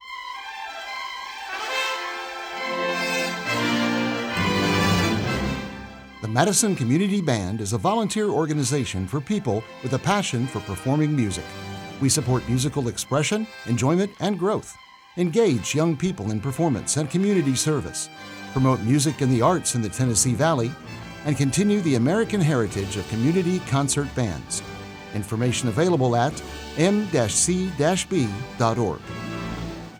PSA About MCB   Future MCB Performances